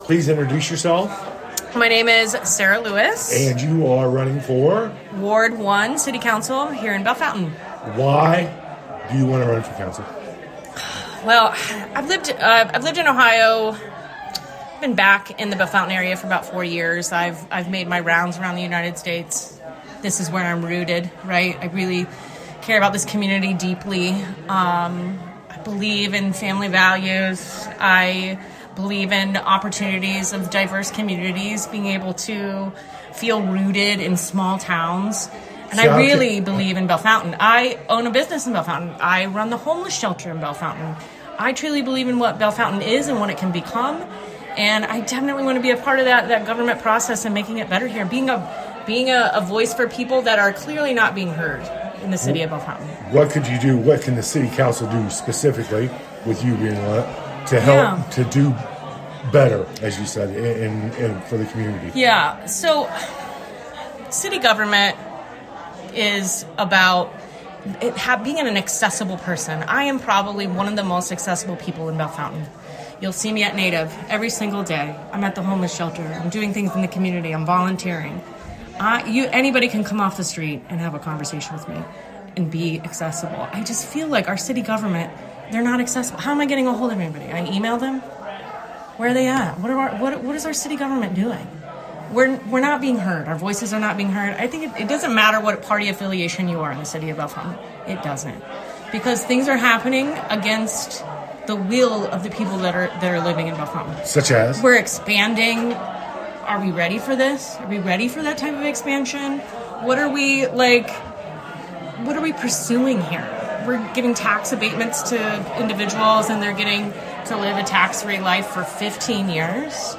The recordings were slightly edited for clarity, but the responses from the candidates are presented in full.